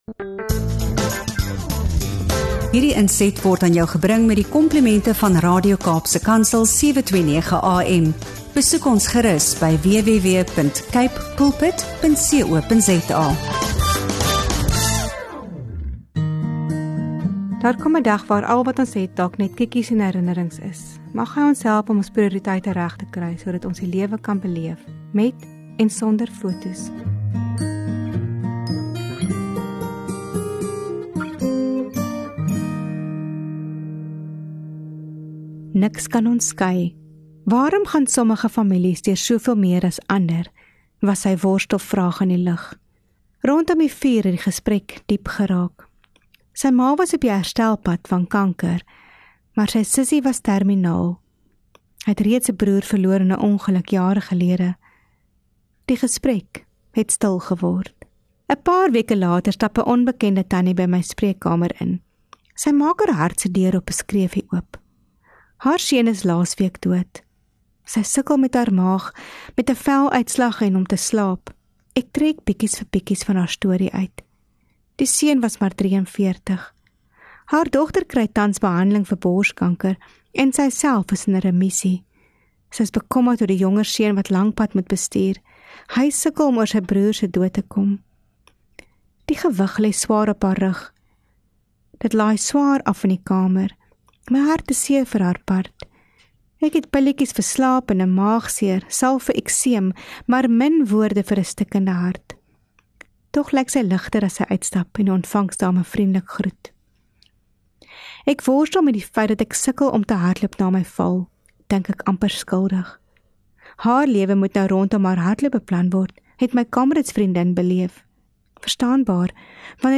Hierdie podcast-episode word vir jou gebring deur Radio Kaapse Kanzel 729AM, met musiek en besinninge uit die hart. Luister in vir 'n vreedsame, opbeurende ervaring